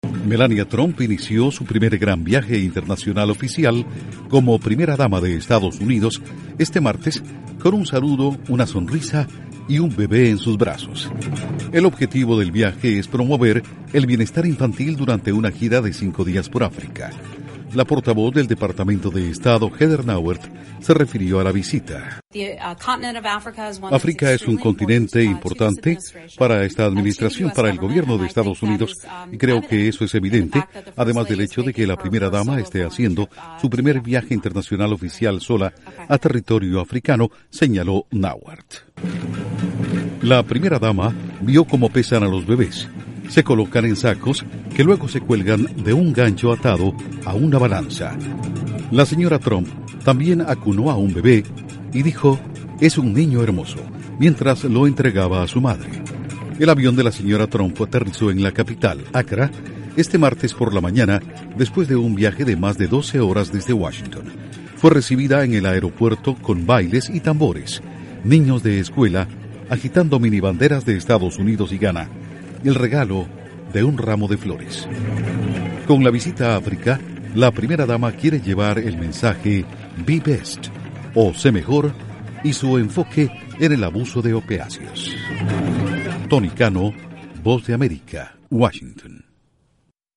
Melania Trump inicia gira por África rodeada de niños, uno de ellos en sus brazos. Informa desde la Voz de América en Washington